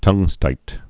(tŭngstīt)